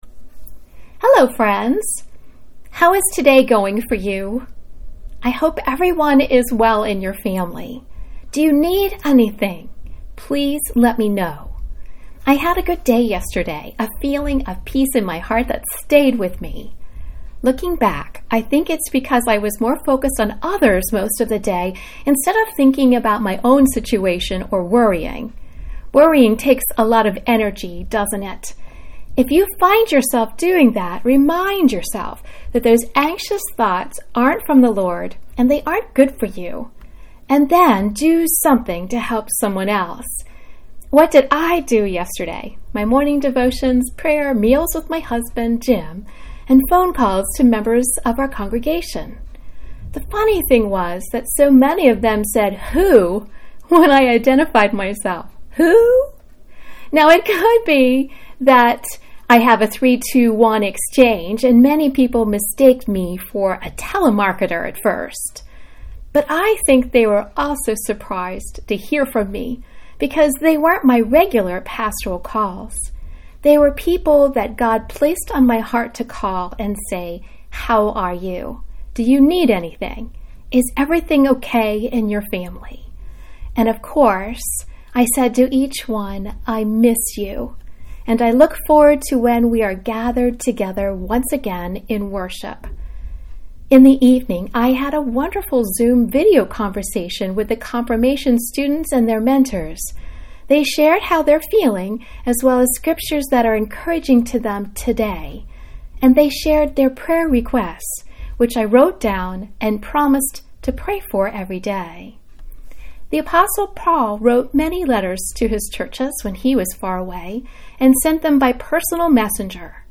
April 2, 2020 Devotion
Music: “I Know Who Holds Tomorrow” by Alison Krauss, The Cox Family.